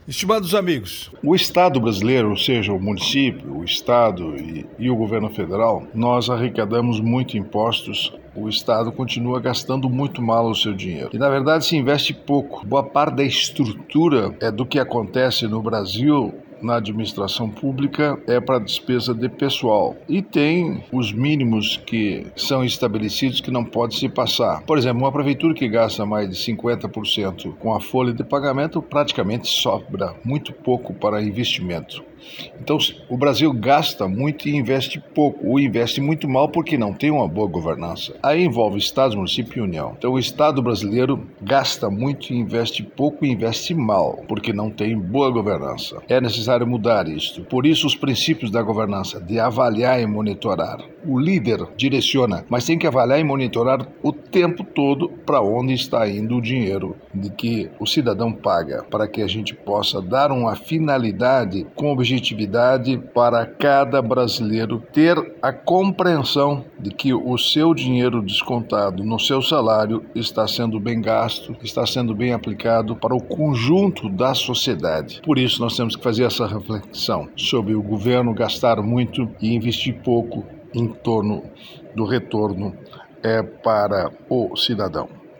Comentário desta quarta-feira (16/10/24) do ministro do TCU Augusto Nardes.